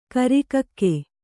♪ kari kakke